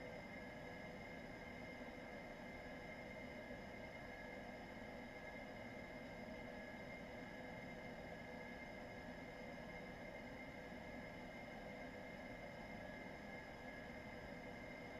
H145_Avionics-right.wav